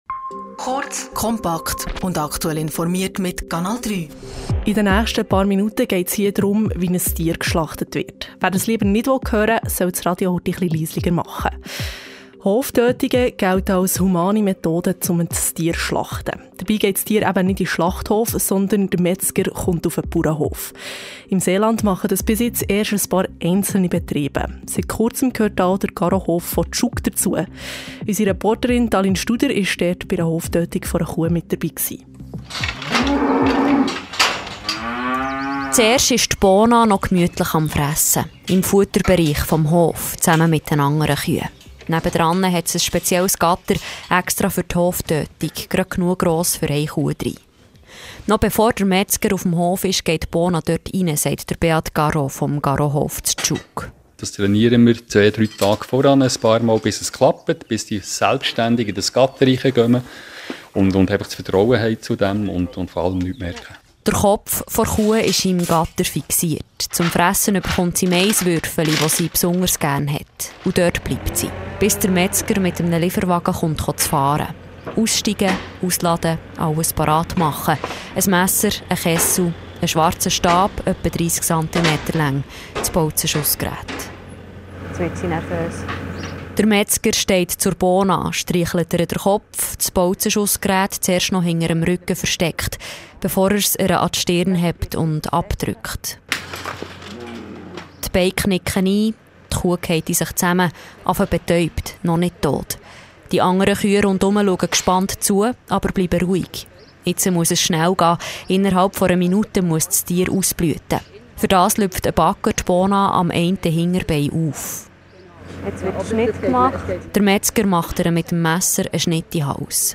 Reportage_Garohof.mp3